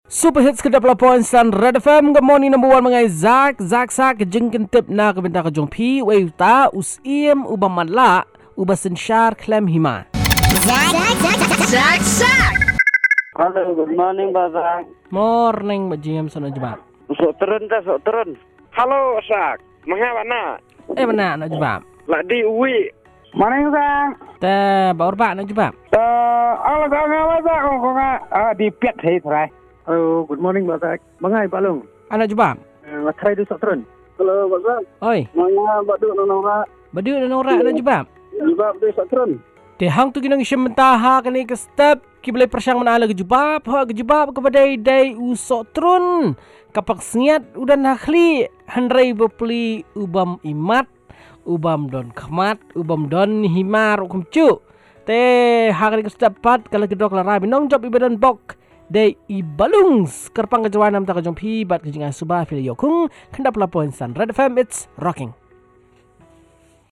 calls and results